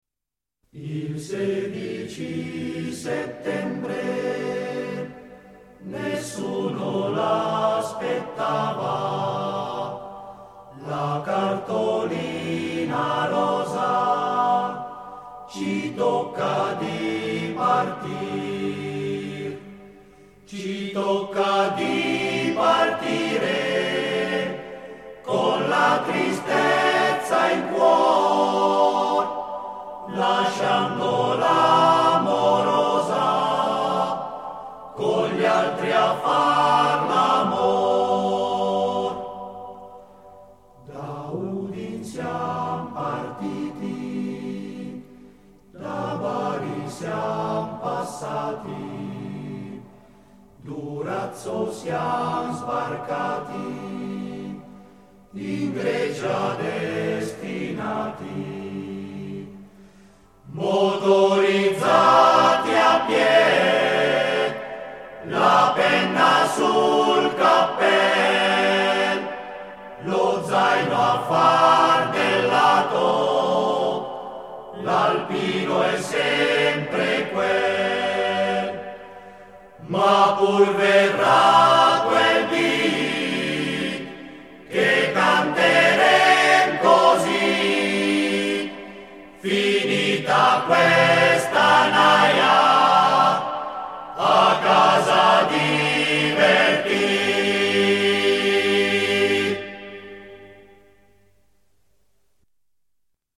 Esecutore: Coro Grigna dell'Associazione Nazionale Alpini sez. di Lecco